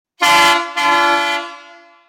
Alarme Sonoro Industrial Modelo 2
Tem como característica o som de baixa frequência e o longo alcance além de atender as exigências legais das áreas de segurança do trabalho e/ou das brigadas de incêndio das empresas.
• 02 Cornetas em plástico de engenharia;
• Intensidade sonora 150db;
• Som de baixa frequência e longo alcance;
• Sistema pneumático;